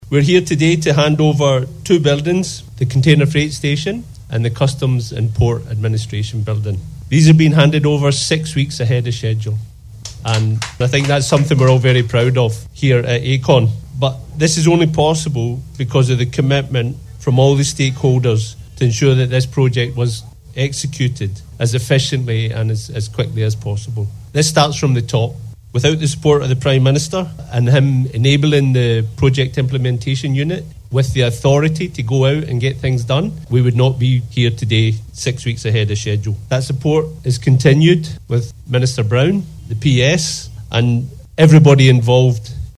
He made this statement during yesterday’s official ceremony held for the handing over of keys for the Buildings on the Port Modernization Project at the site of the Modern Port in Kingstown.